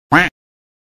QUACK
QUACK - Tono movil
Quack.mp3